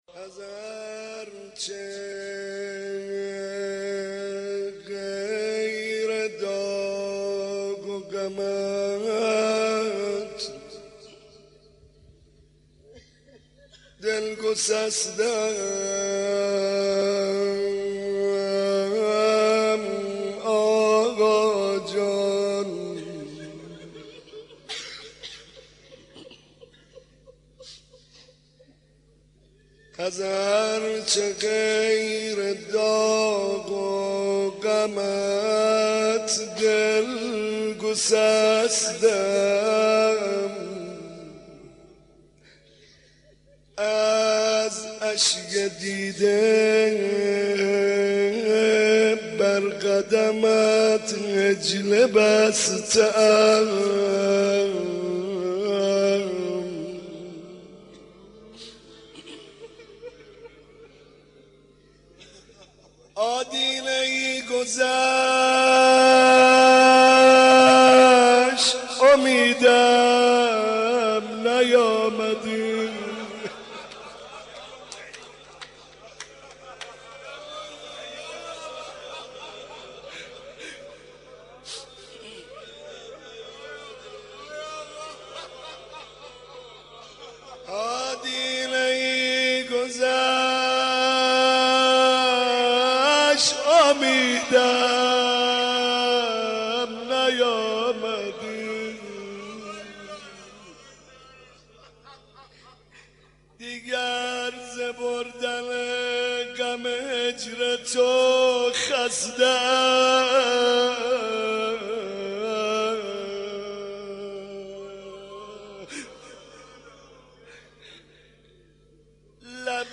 محمدرضا طاهری مداح
مناسبت : دهه دوم محرم